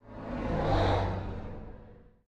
car4.ogg